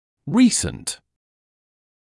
[‘riːsnt][‘риːснт]недавний, свежий (по времени)